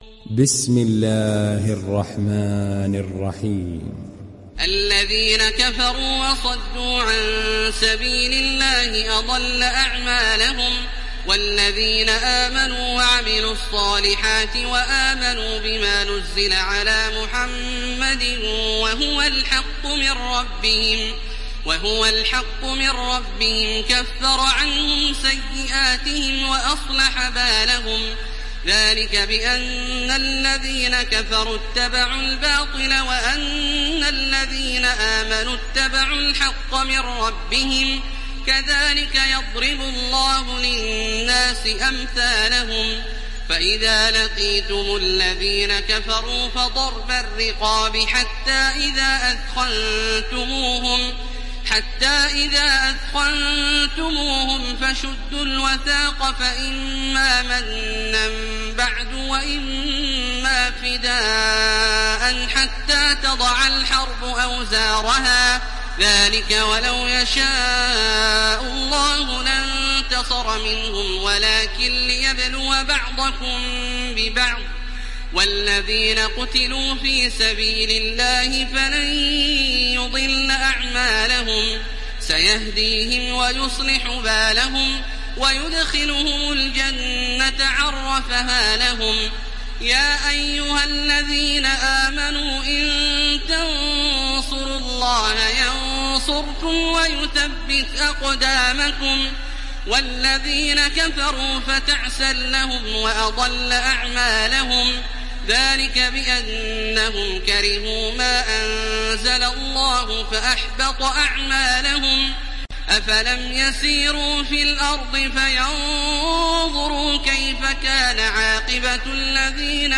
Muhammed Suresi İndir mp3 Taraweeh Makkah 1430 Riwayat Hafs an Asim, Kurani indirin ve mp3 tam doğrudan bağlantılar dinle
İndir Muhammed Suresi Taraweeh Makkah 1430